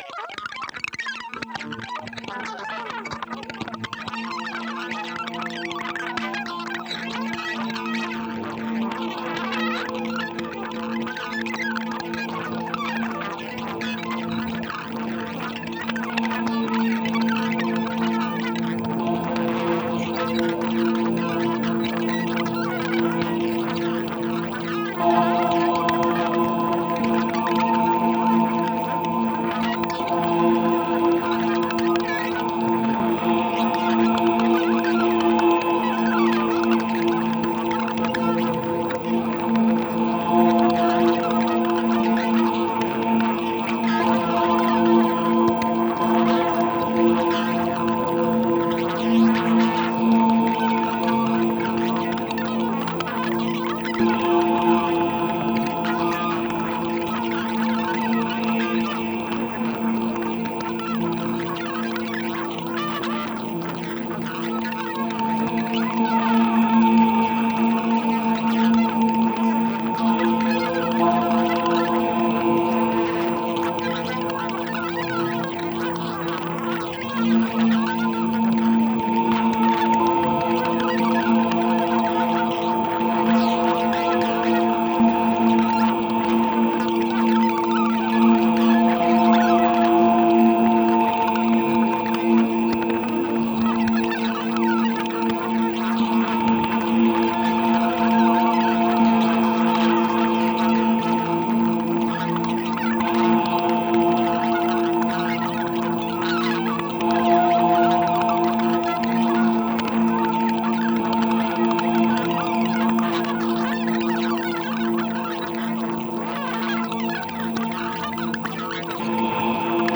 ギター愛好家の方々にはもちろん、現代音楽、先端的テクノ、実験音楽をお好きな方々にもお薦めのアルバムです。